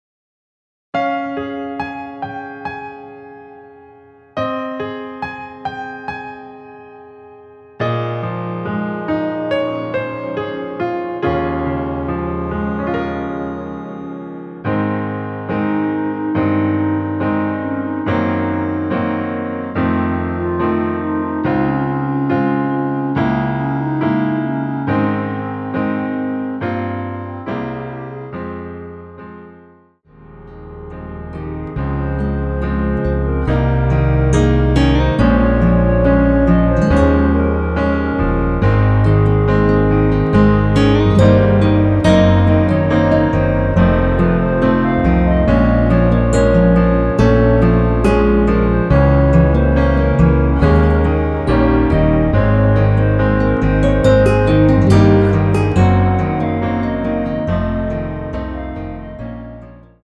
원키에서(-2)내린 멜로디 포함된 MR입니다.
Ab
◈ 곡명 옆 (-1)은 반음 내림, (+1)은 반음 올림 입니다.
노래방에서 노래를 부르실때 노래 부분에 가이드 멜로디가 따라 나와서
앞부분30초, 뒷부분30초씩 편집해서 올려 드리고 있습니다.
중간에 음이 끈어지고 다시 나오는 이유는